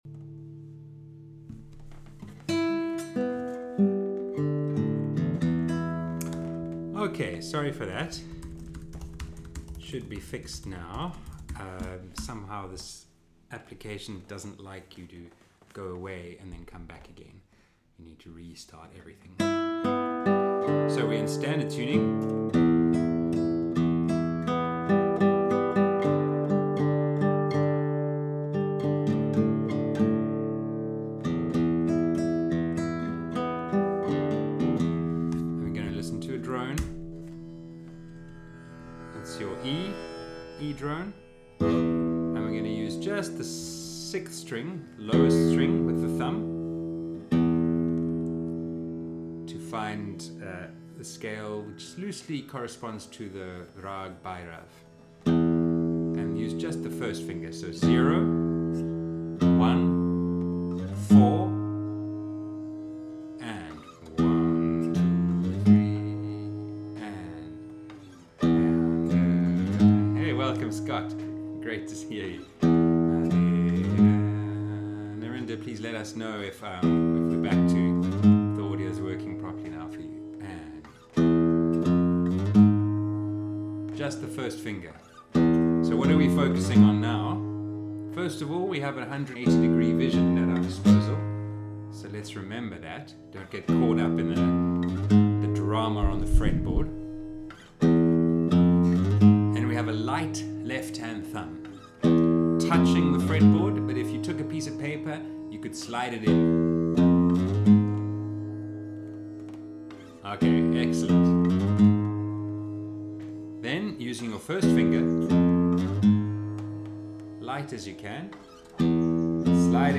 Video lessons uploaded after every online group class.